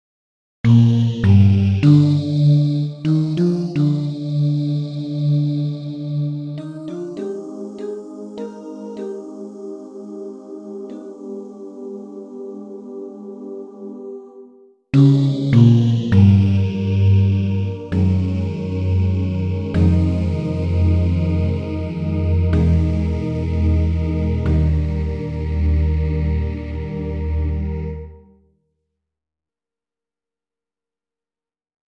Key written in: E♭ Major